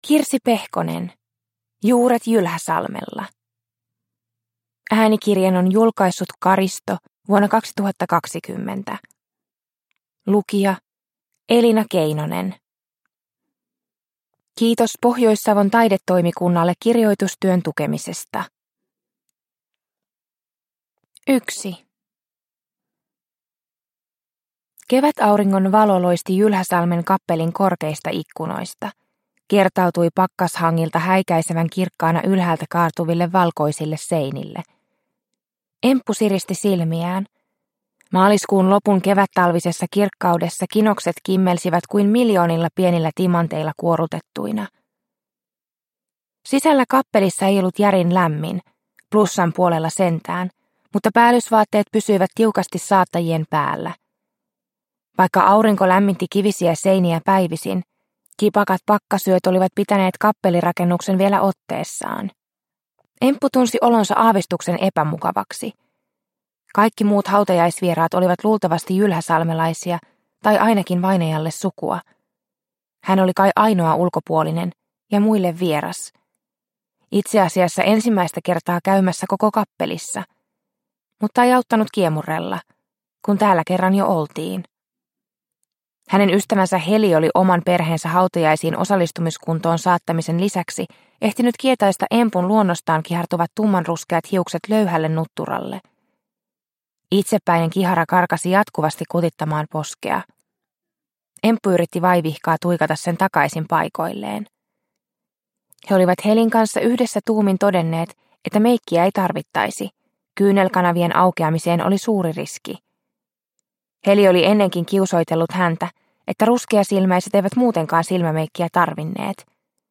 Juuret Jylhäsalmella – Ljudbok – Laddas ner